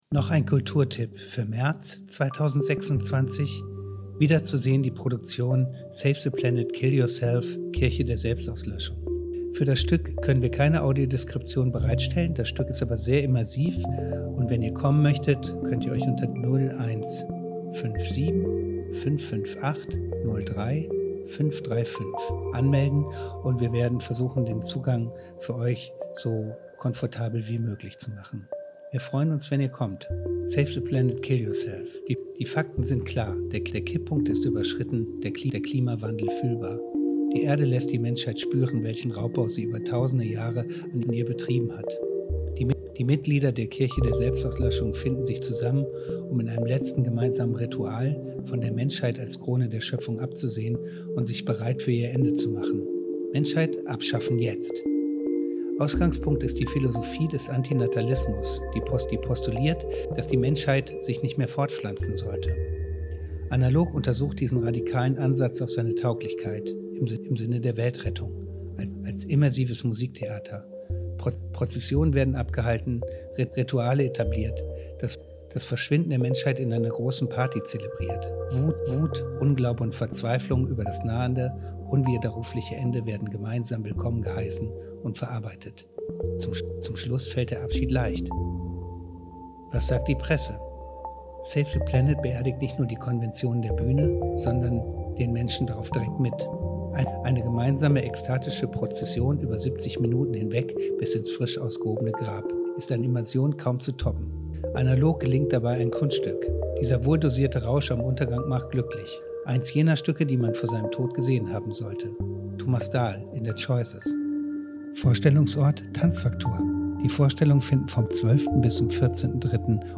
Audioflyer